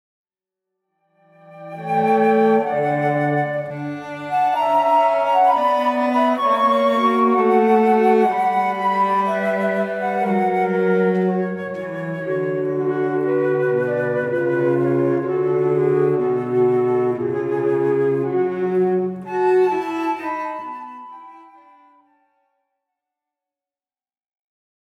en ré majeur-Preludio-Largo